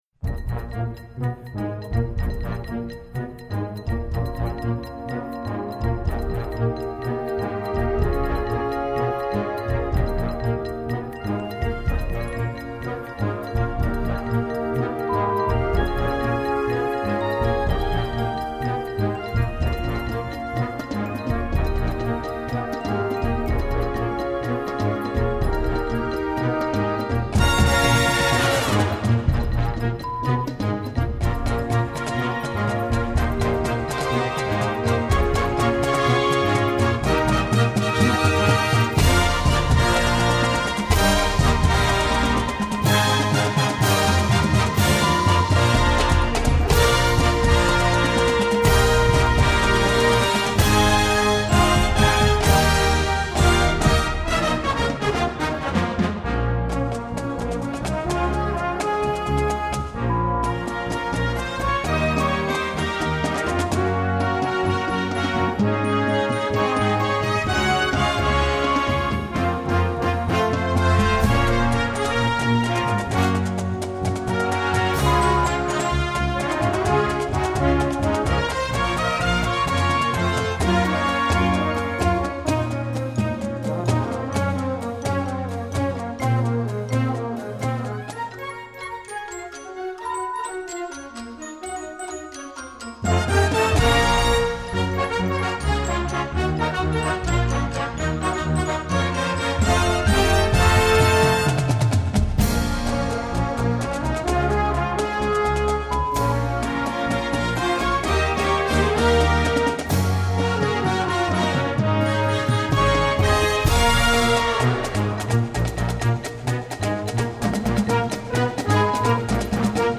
Gattung: Performance/Easy Limited Edition
Besetzung: Blasorchester